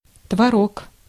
Ääntäminen
US : IPA : [tʃiːz]